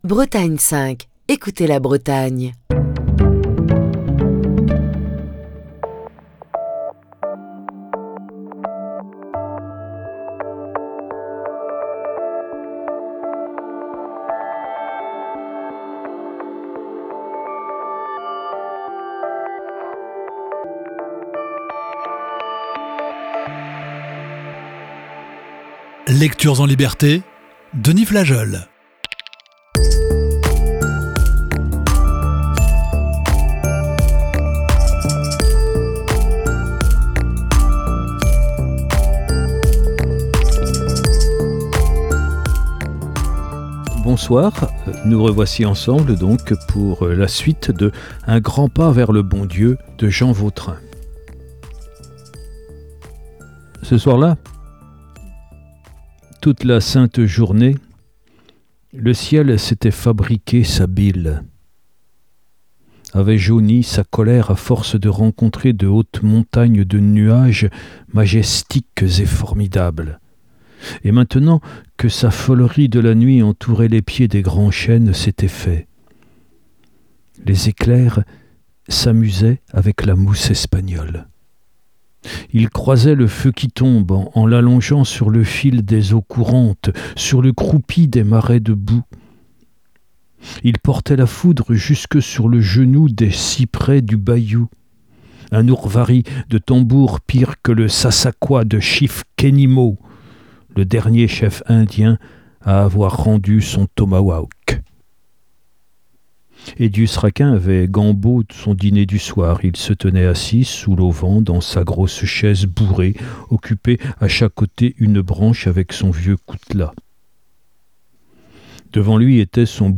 la lecture de la deuxième partie de ce récit